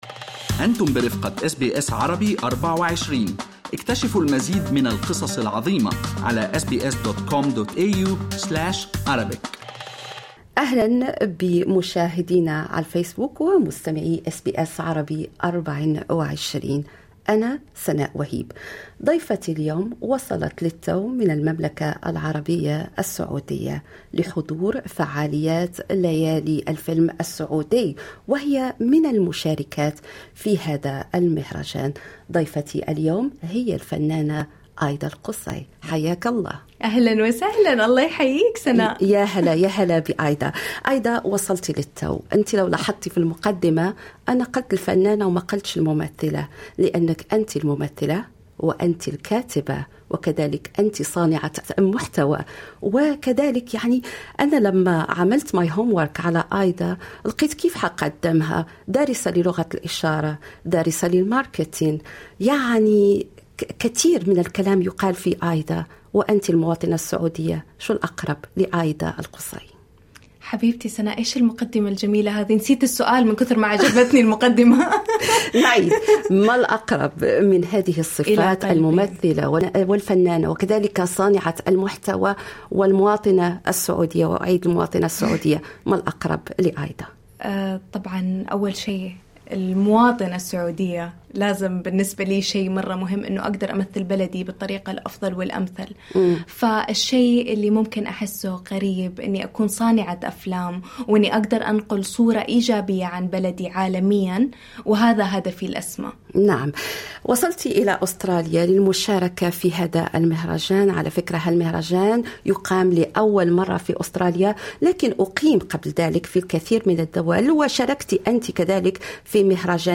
استوديوهات أس بي أس عربي24 بسيدني
اللقاء الكامل